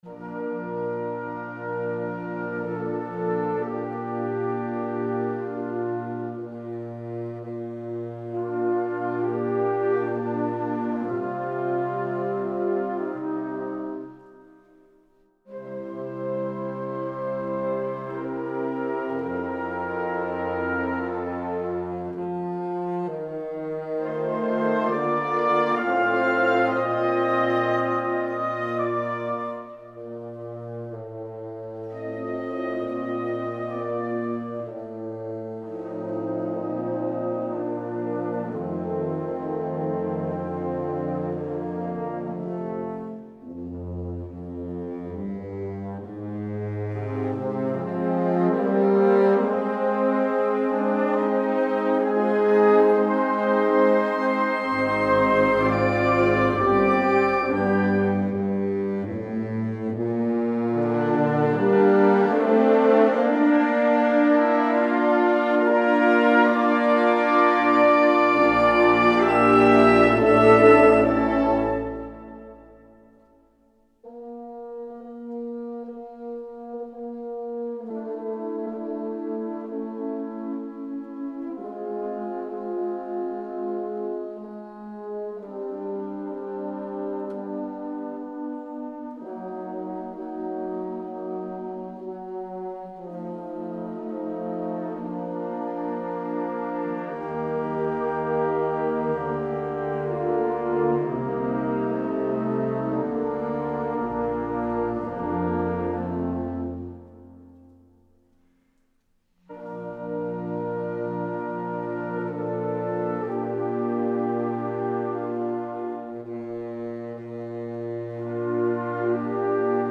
Besetzung: Blasorchester
Genre: Kirchenmusik